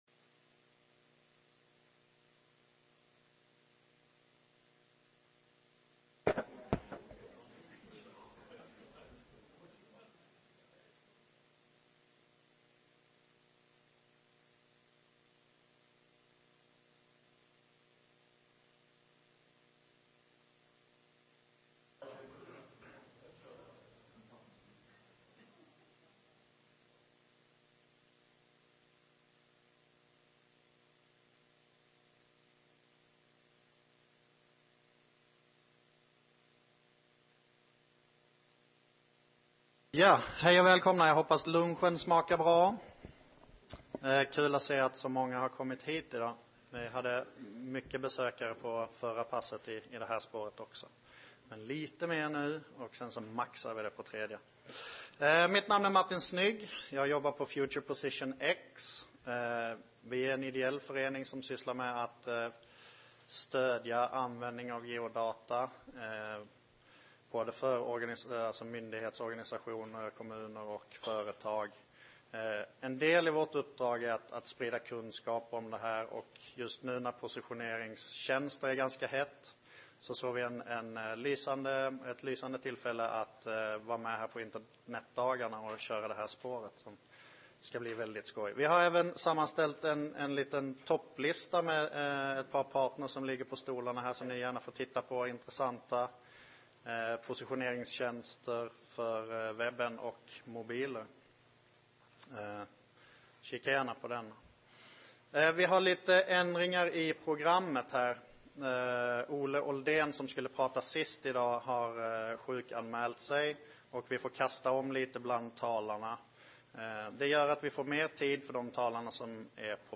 Väder, kaféer med surfzoner och närmaste bankkontor är några exempel på tjänster som finns idag och som utnyttjar användarens position för att ge bästa möjliga svar. På det här seminariet presenteras en rad tjänster och vi får ta del av grundarnas erfarenheter av vad som fungerar och inte fungerar.